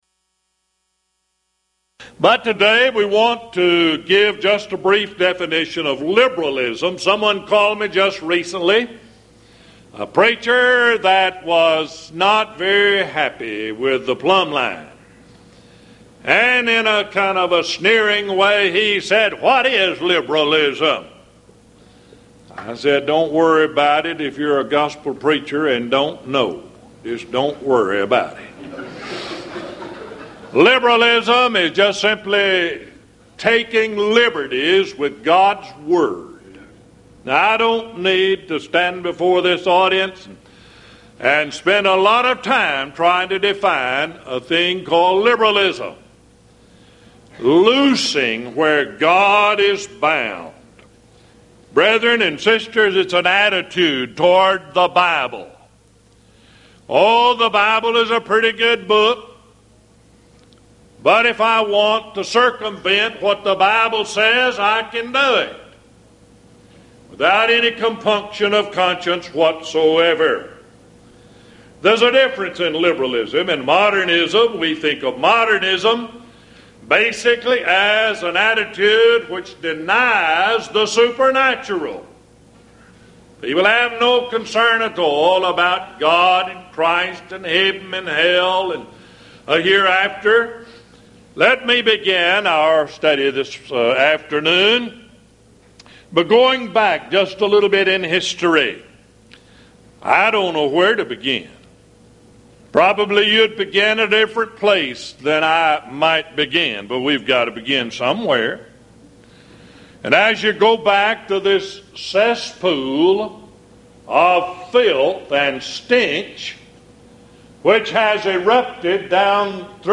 Series: Houston College of the Bible Lectures
lecture